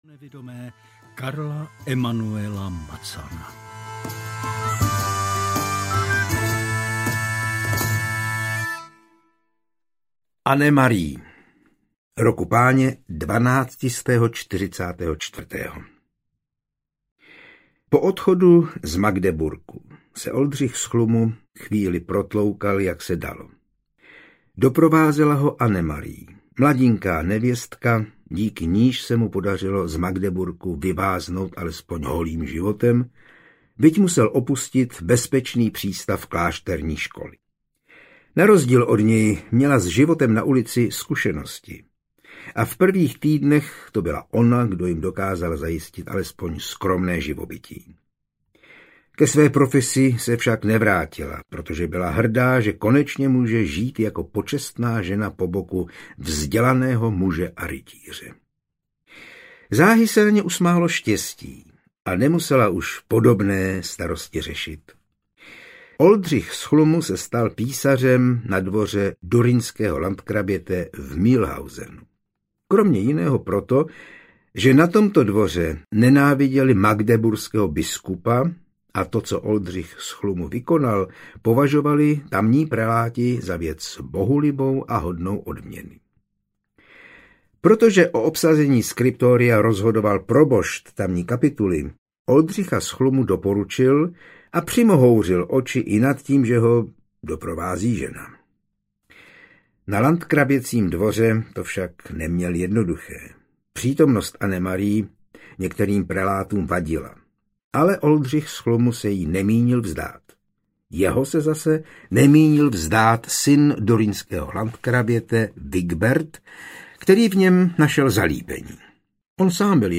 Ukázka z knihy
ctyri-pripady-mladeho-oldricha-z-chlumu-audiokniha